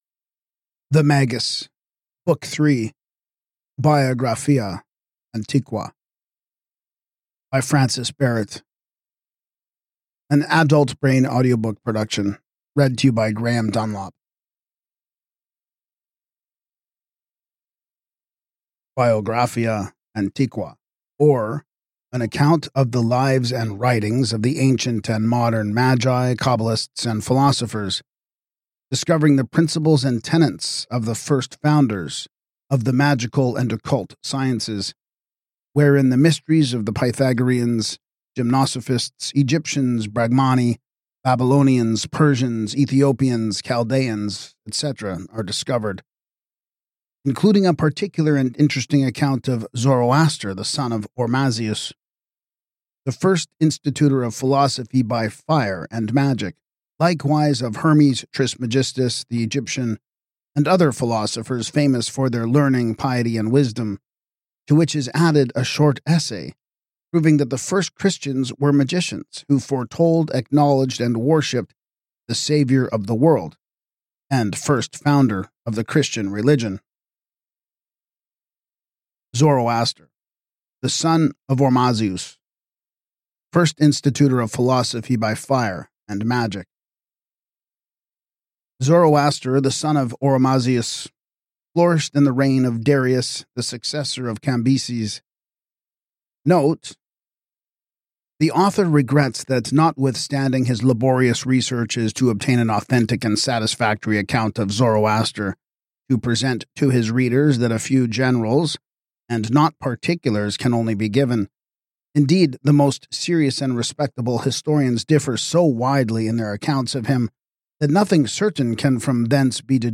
Podcast (audiobooks)